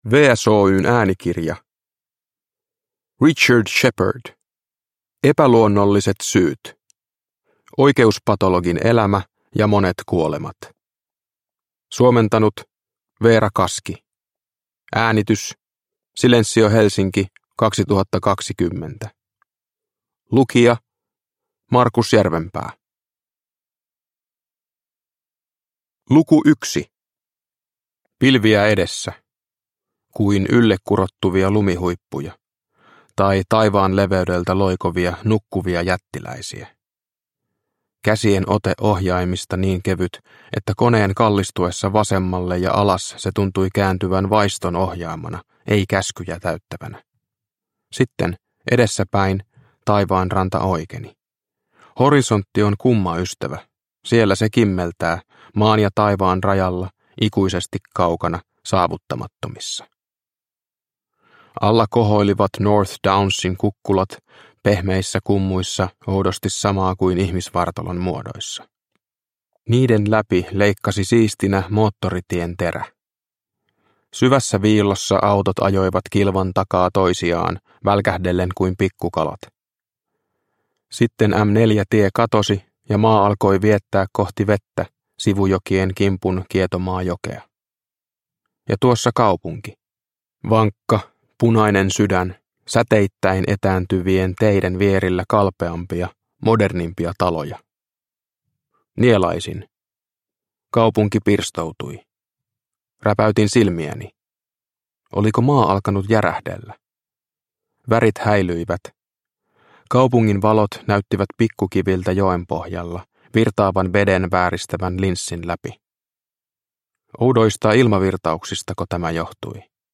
Epäluonnolliset syyt – Ljudbok – Laddas ner